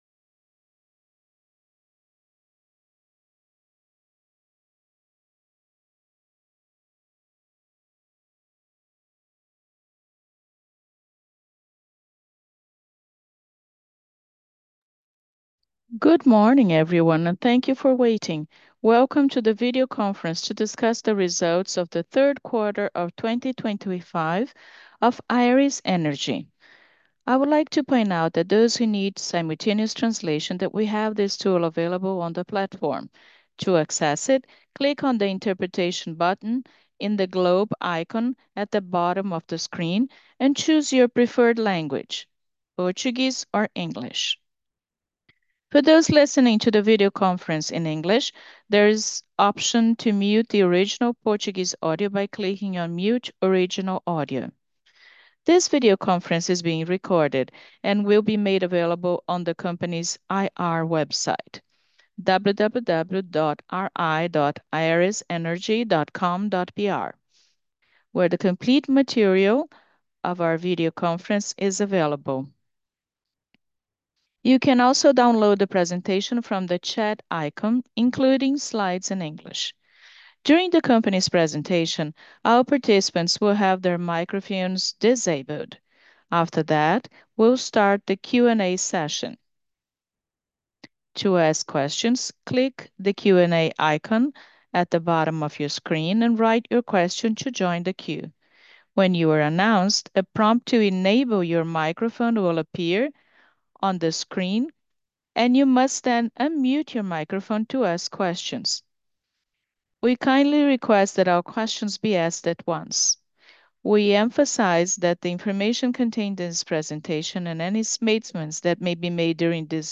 teleconference_3t25.mp3